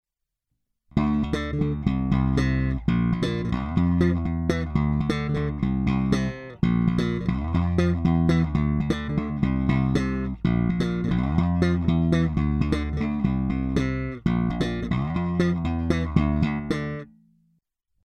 Není-li uvedeno jinak, následující nahrávky jsou vyvedeny rovnou do zvukové karty a kromě normalizace ponechány bez zásahů.
Slap paralelně